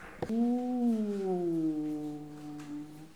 Les sons ont été découpés en morceaux exploitables. 2017-04-10 17:58:57 +02:00 546 KiB Raw History
bruit-animal_12.wav